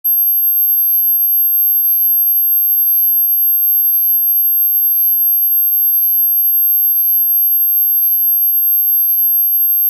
/128kbps) Описание: Ультра звук частотой 17 Кгц (17000 Гц). Mosquito.
ultra_zvuk_.mp3